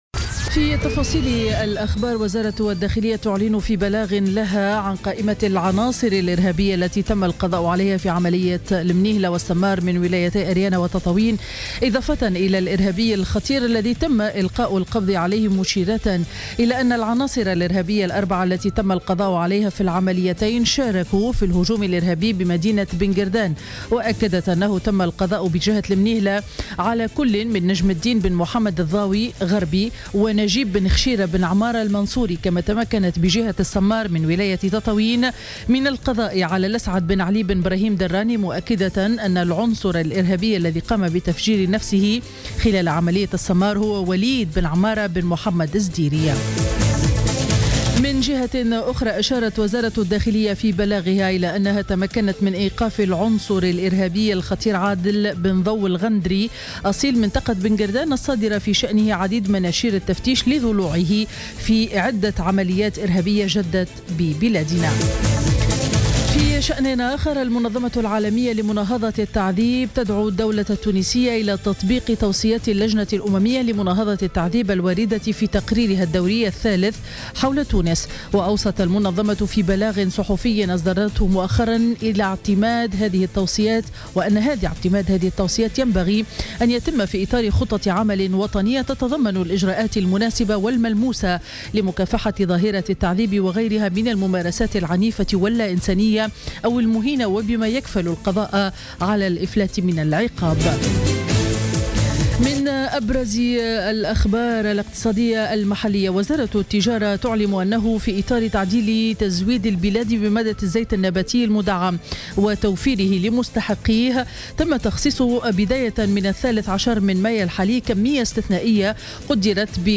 نشرة أخبار السابعة صباحا ليوم الأحد 15 ماي 2016